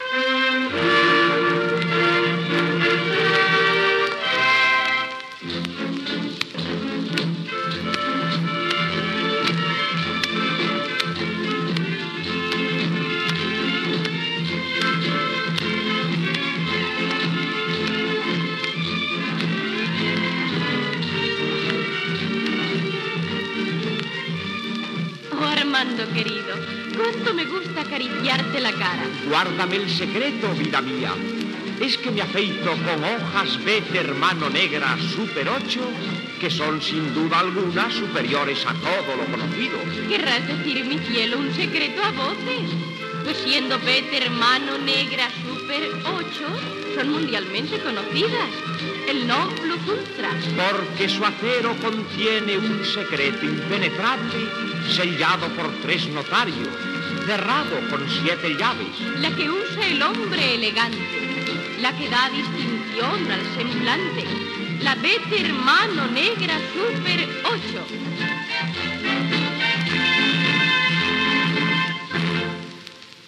Publicitat cantada